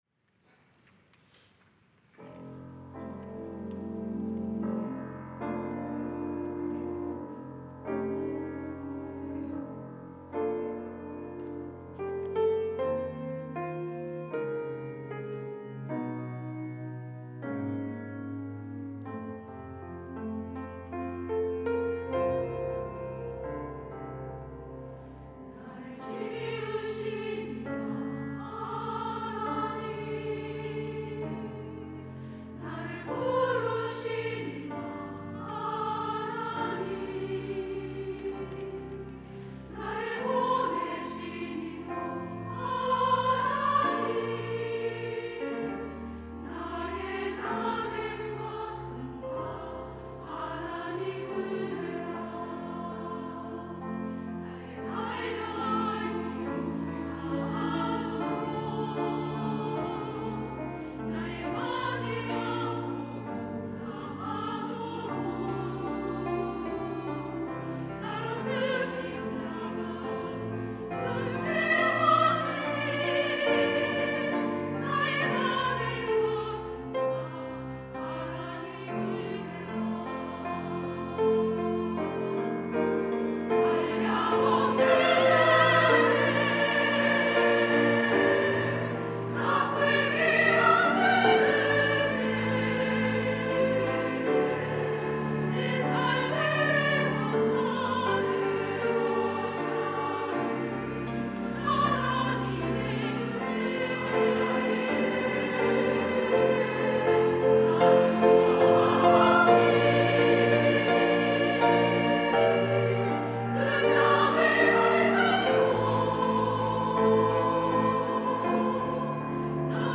여전도회 - 하나님의 은혜
찬양대